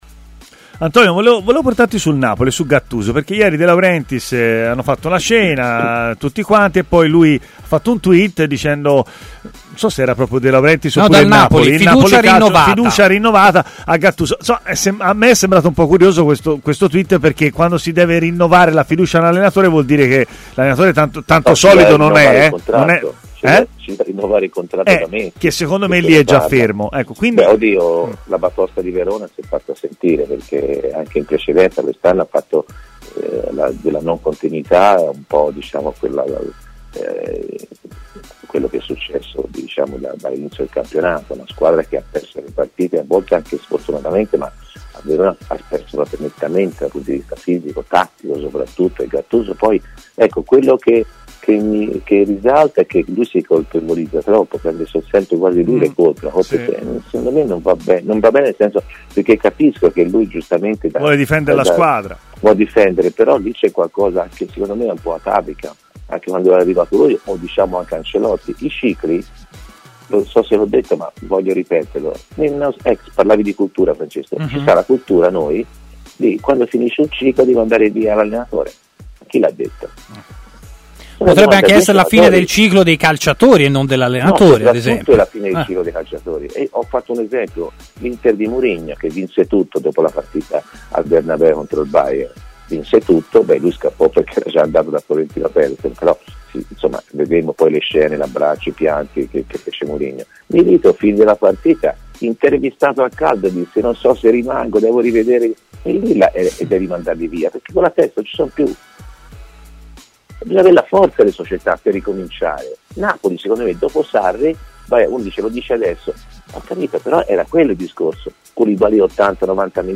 L'ex centrocampista Antonio Di Gennaro, oggi commentatore tv e opinionista TMW Radio, è intervenuto a Stadio Aperto.